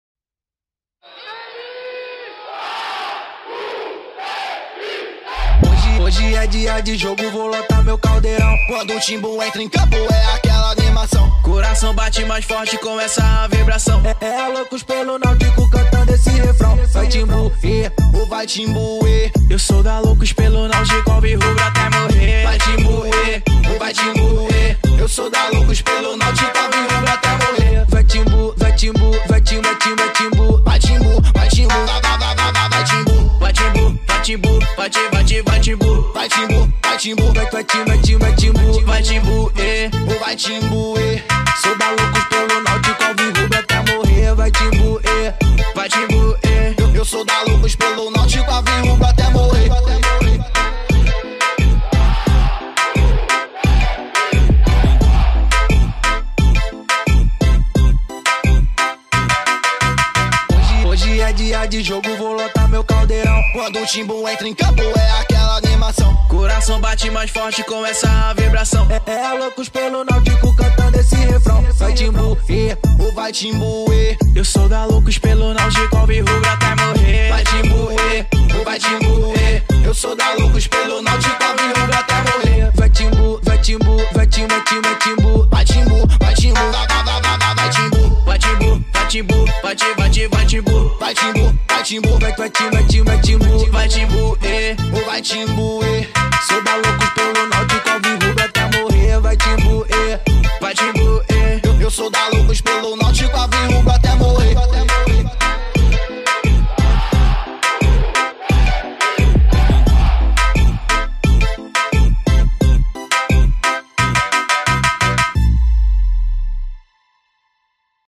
EstiloBrega Funk